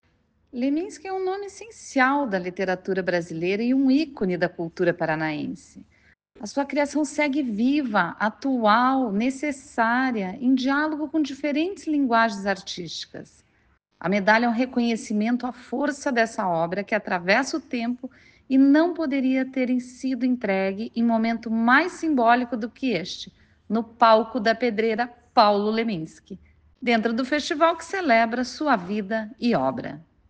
Sonora da secretária da Cultura, Luciana Casagrande Pereira, sobre a homenagem para Paulo Leminski